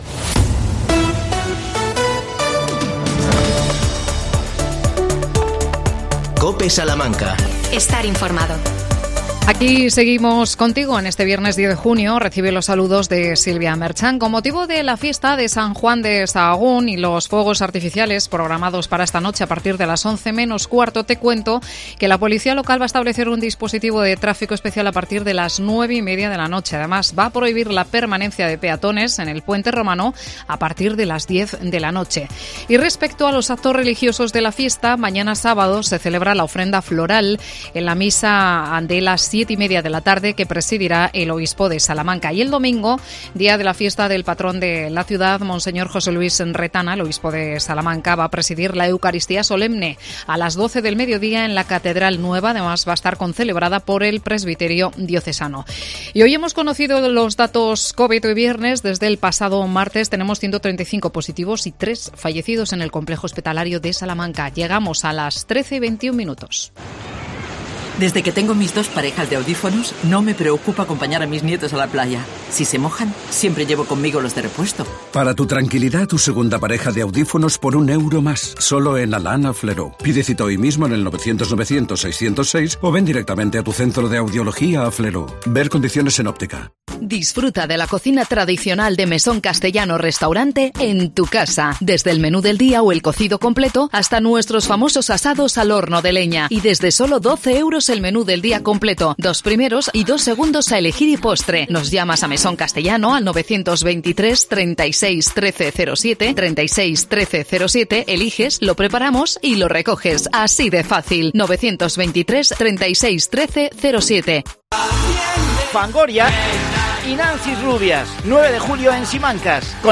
AUDIO: Entrevista a Pedro Samuel Martín, alcalde de Carbajosa de la Sagrada.